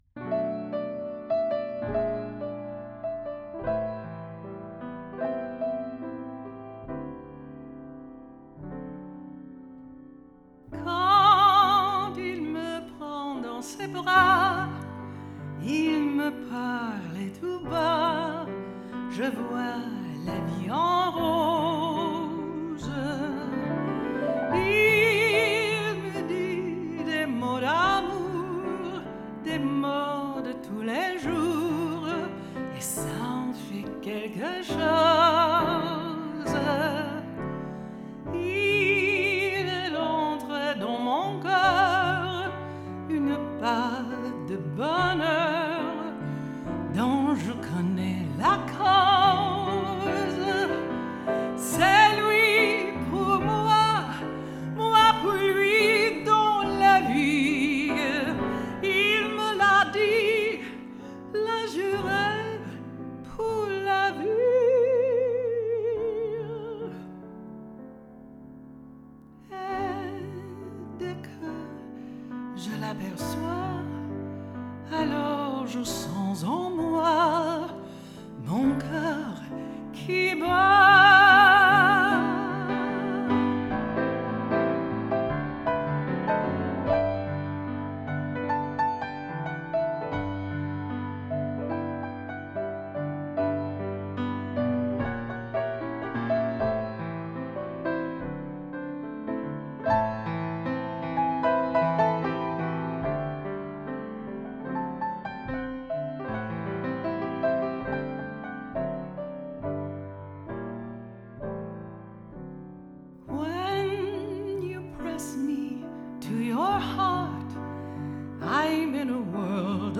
Hands on piano
Vocals